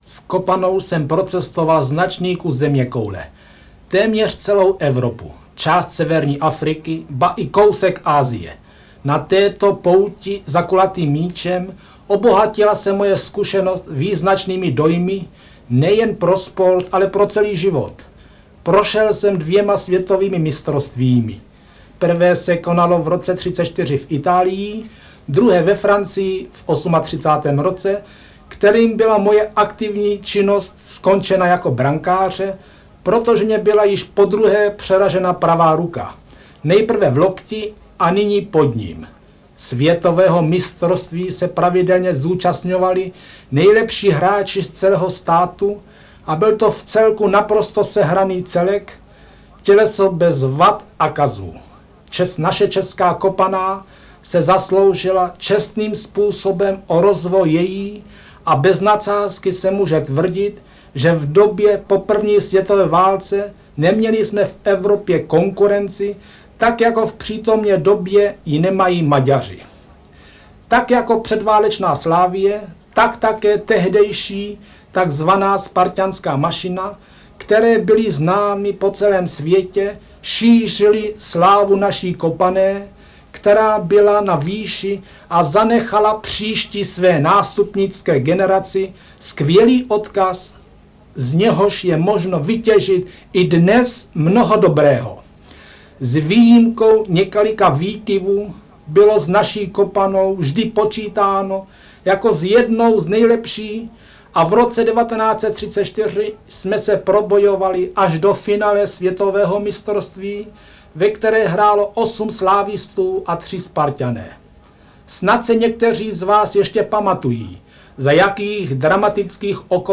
- František Plánička - Audiokniha
• Čte: František Plánička